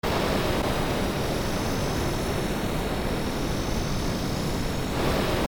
thruster.mp3